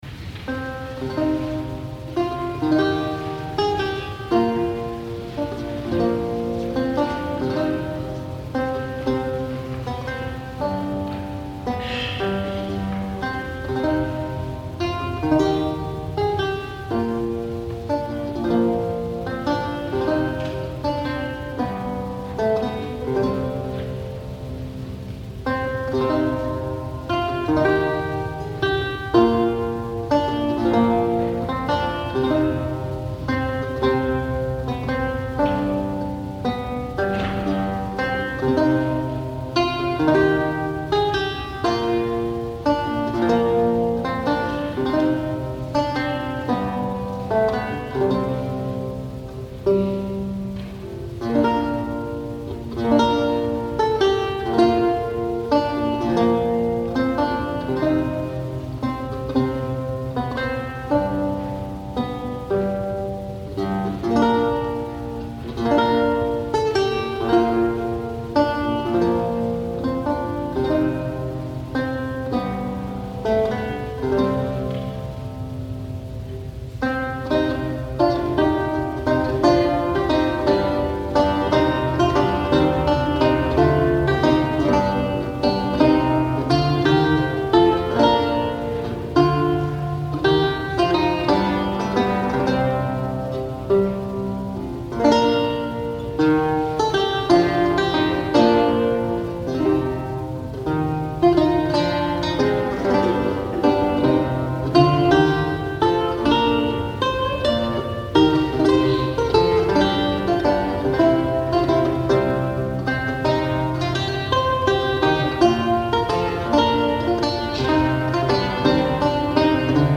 Lute